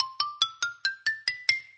xylo_scale.ogg